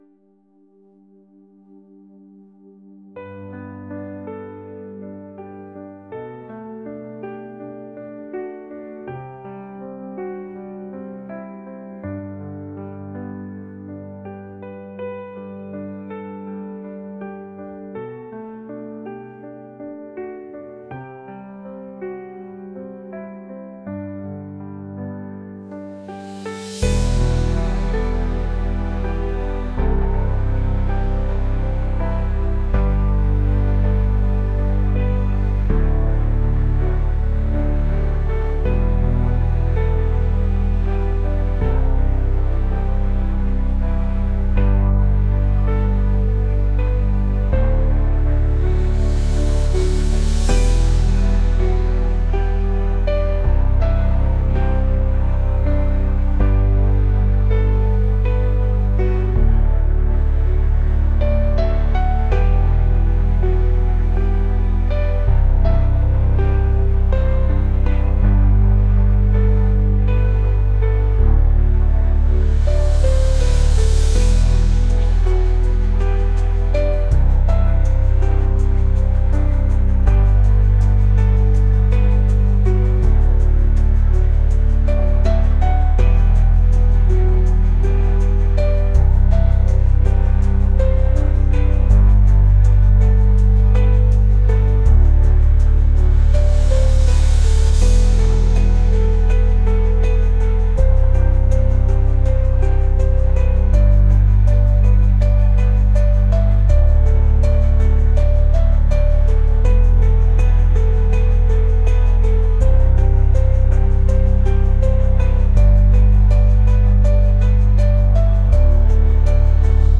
pop | ambient | indie